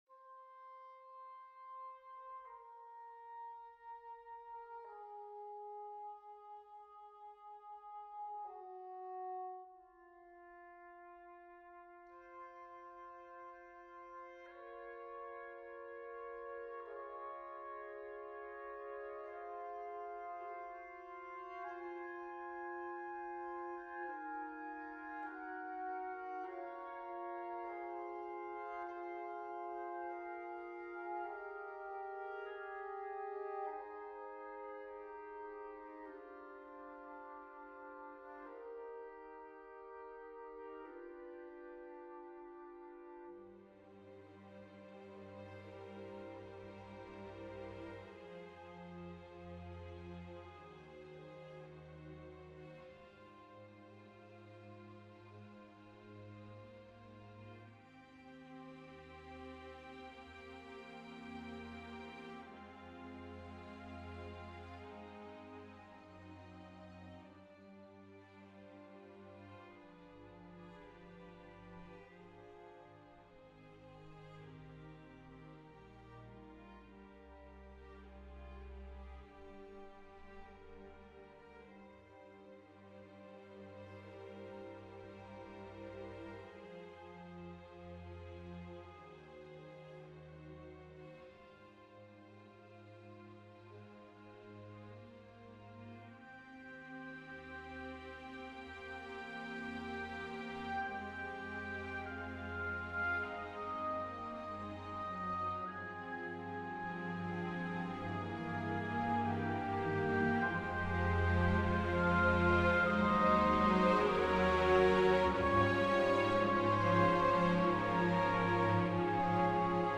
Sigh - Symphonic Orchestra